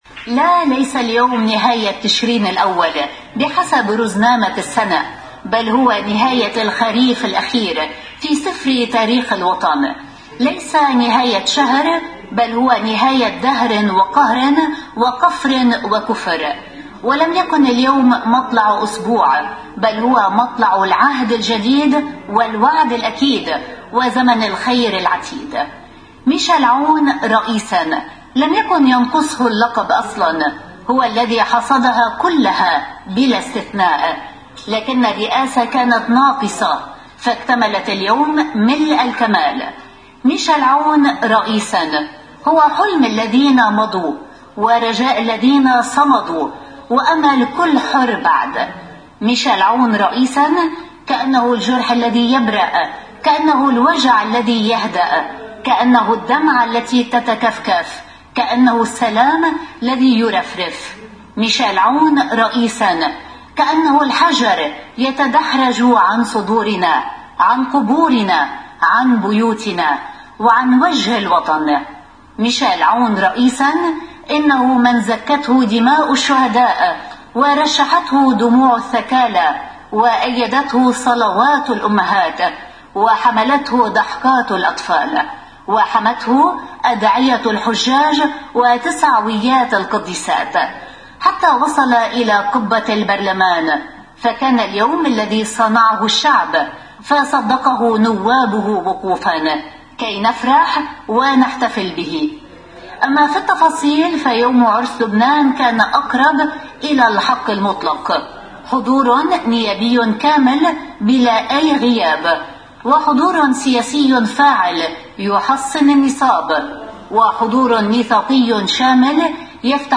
مقدمة نشرة الotv ليلة انتخاب العماد ميشال عون رئيساً للجمهورية اللبنانية: